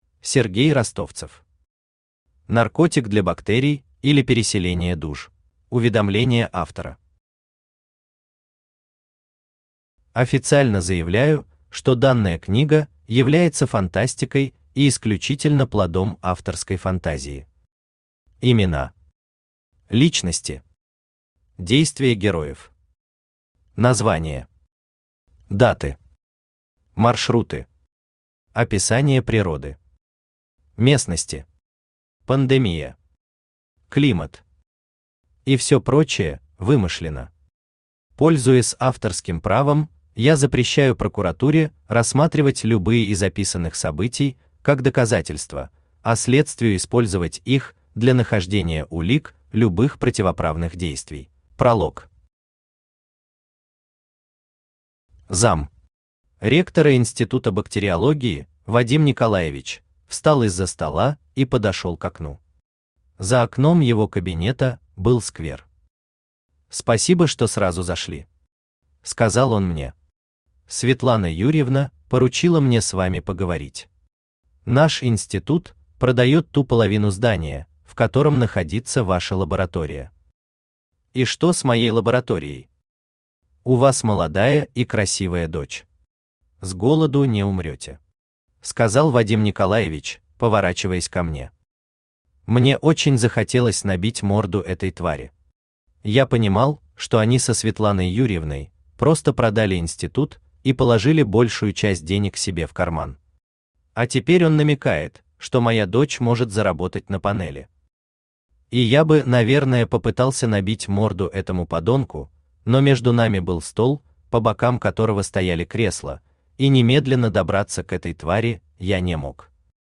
Аудиокнига Наркотик для бактерий, или Переселение душ | Библиотека аудиокниг
Aудиокнига Наркотик для бактерий, или Переселение душ Автор Сергей Юрьевич Ростовцев Читает аудиокнигу Авточтец ЛитРес.